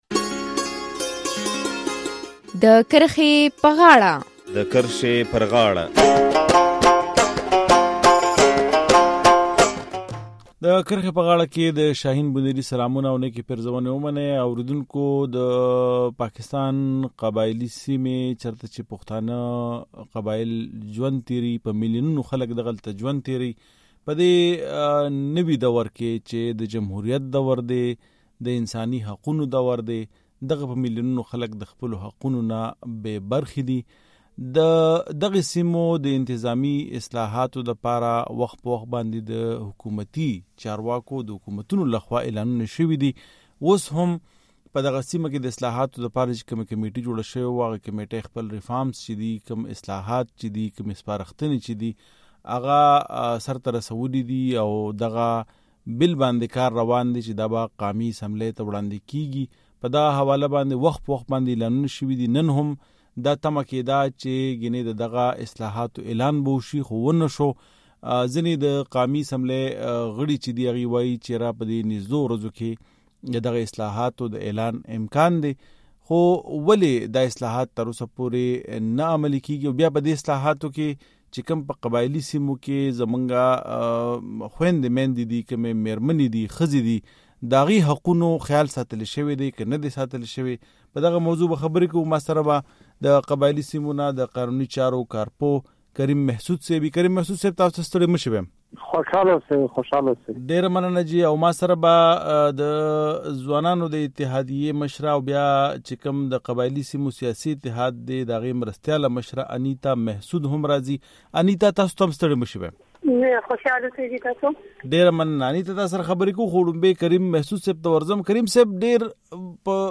د کرښې پر غاړه خپرونه کې په دغه موضوع بحث کوو